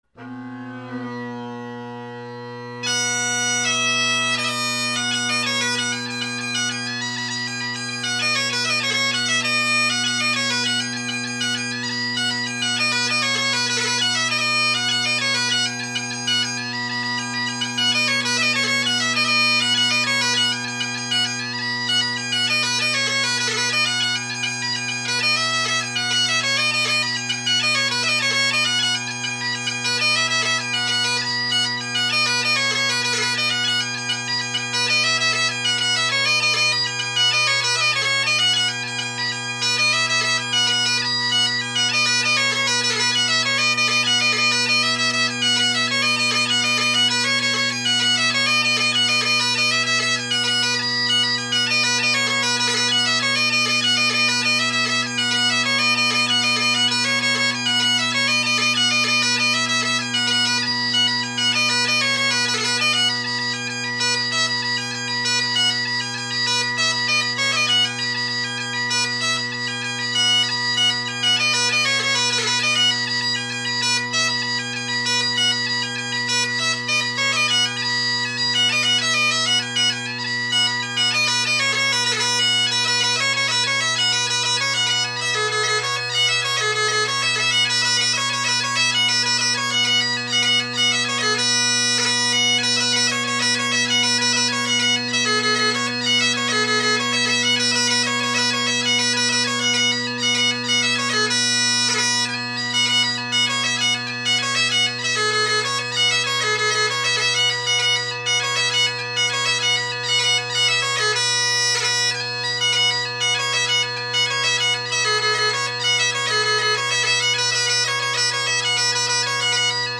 This chanter is louder than any other I’ve recorded, so pay attention to your speaker/headphone volume.
Mark Sheridan and Angus MacKenzie of Dumbarton – facing the mic
The pipes played are my Colin Kyo bagpipe with Ezee tenors and short inverted Ezee bass.